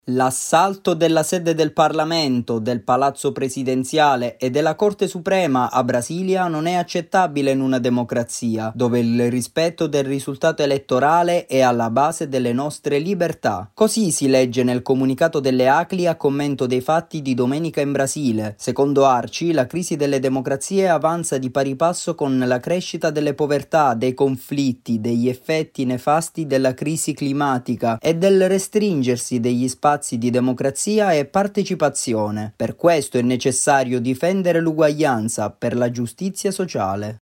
Arci e Acli condannano l’assalto alle istituzioni in Brasile. Il servizio